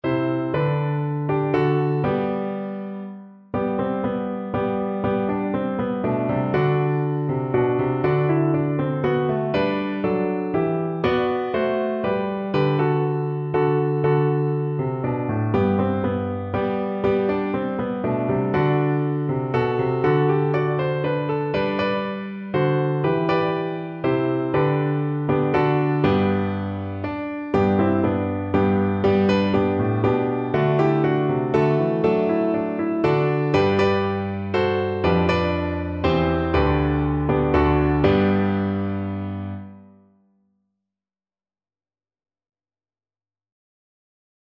Viola
3/4 (View more 3/4 Music)
G major (Sounding Pitch) (View more G major Music for Viola )
Lively ( = c.120)
Traditional (View more Traditional Viola Music)